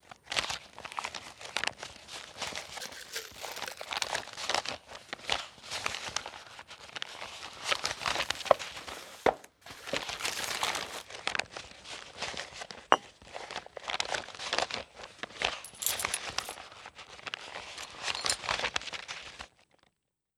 Searching.wav